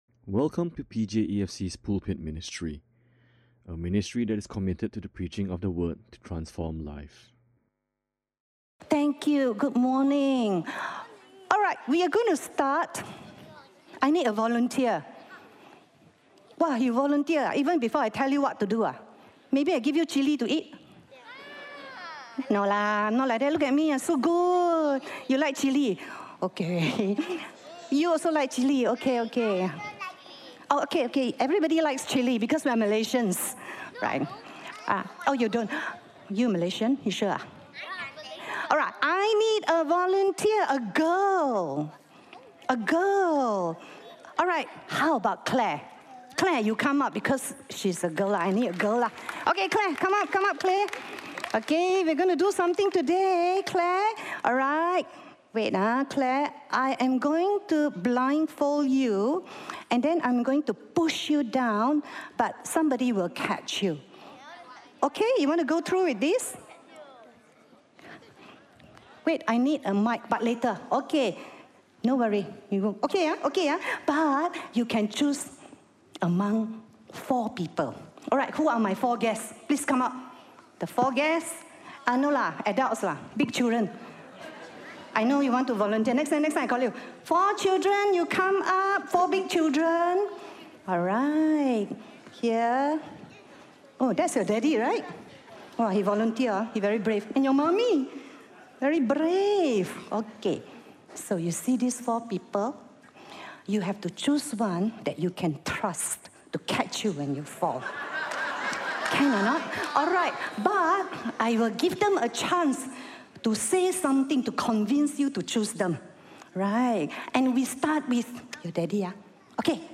This is a stand-alone sermon.
Listen to Sermon Only